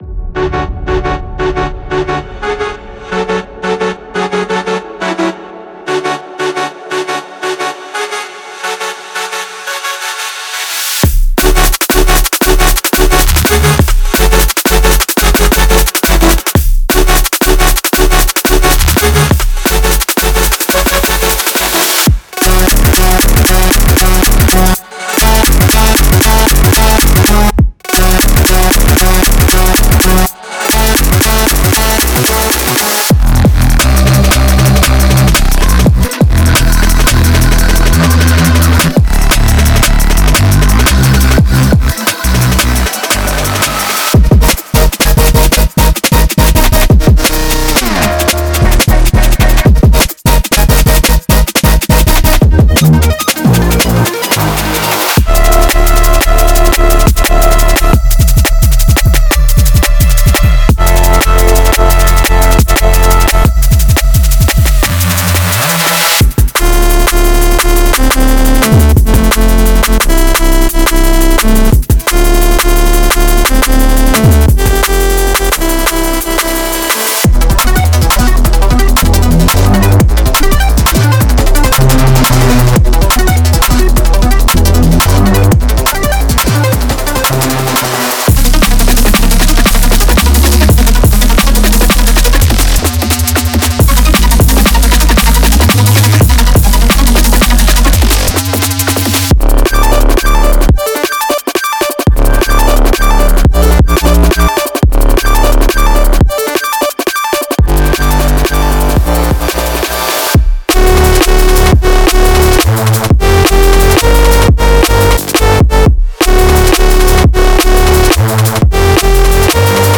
Genre:Drum and Bass
未来的なテクスチャと転がるようなグルーヴを備えた多彩な音のパレットを提供します。
• ミックスに電流のように流れ込む、表情豊かなシンセと贅沢なアルペジオ
• 感情とハーモニーの動きを与えるカラフルなコード
• 重量感と個性をもたらす、荒々しくも表現力豊かなベースサウンド
• 足を動かし、常識を打ち破るために設計されたグルーヴィーでクセのあるドラム
デモサウンドはコチラ↓